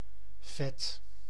Ääntäminen
IPA: /vɛt/